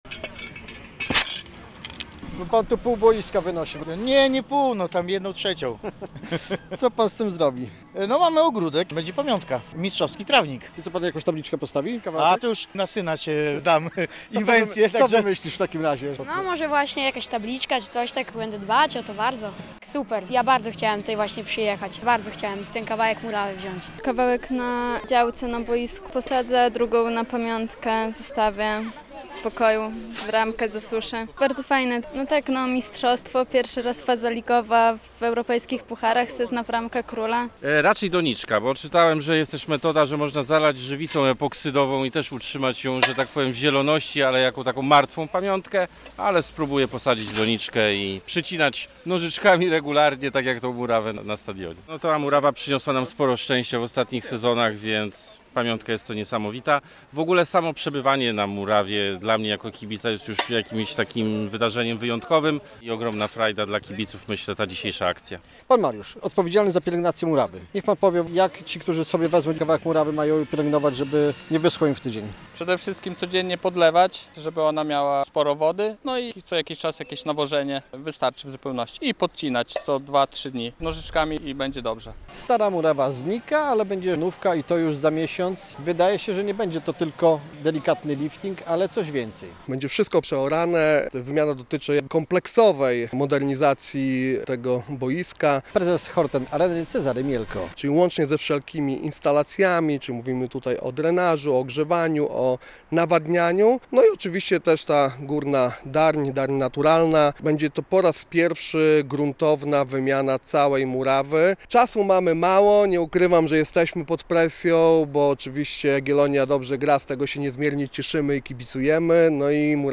Pożegnanie dotychczasowej murawy Chorten Areny - relacja